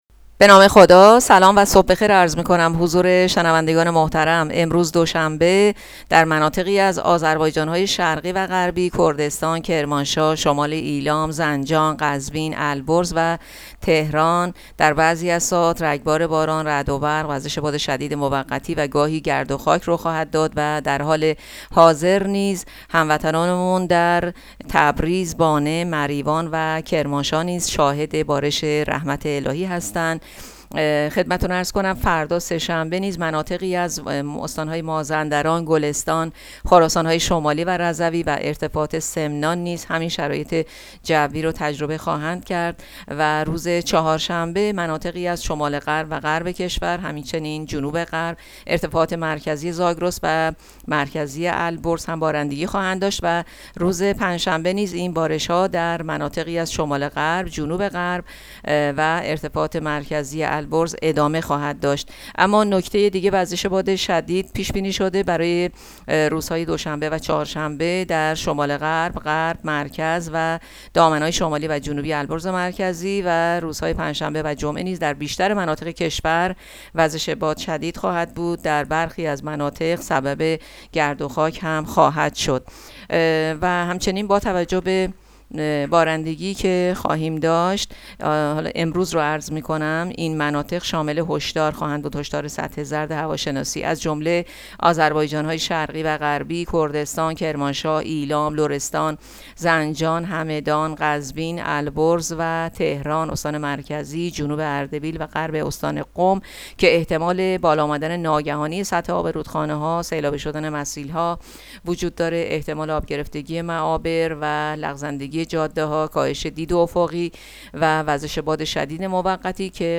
گزارش رادیو اینترنتی پایگاه‌ خبری از آخرین وضعیت آب‌وهوای ۱۱ فروردین؛